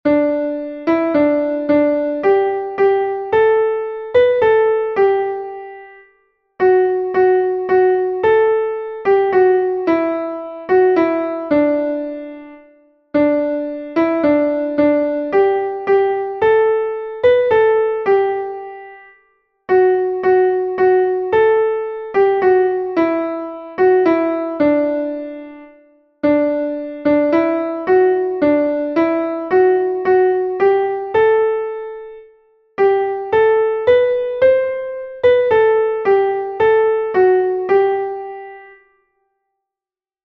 „Ännchen von Tharau“ ist ein zeitloses Volkslied, das tief in der deutschsprachigen Kultur verwurzelt ist.
Einstimmige Melodie im Violinschlüssel, G-Dur, 3/4-Takt, mit der 1.
aennchen-von-tharau_klavier_melodiemeister.mp3